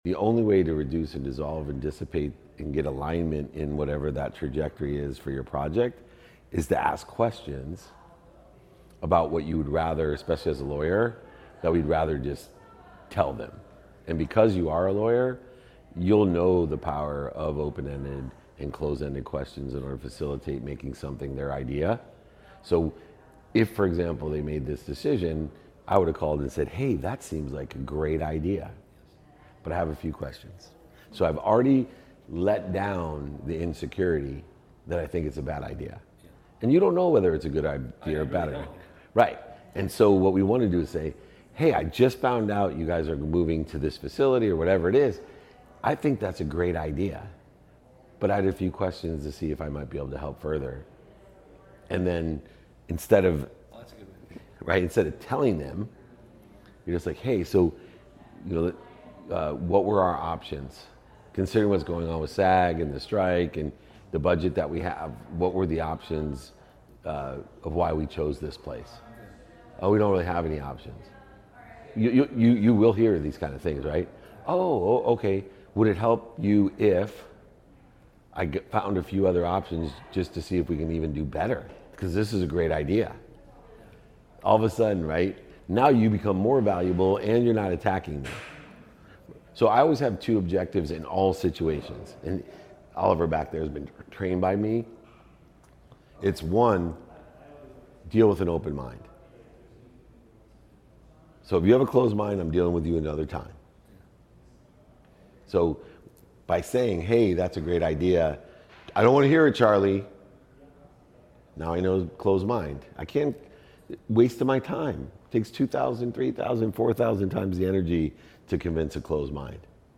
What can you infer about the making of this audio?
Today's episode features insights from my talk at the Champman Meet-up this past October. In this episode, I discuss the power of open-ended questioning in achieving project alignment and decision-making, especially from a lawyer's perspective.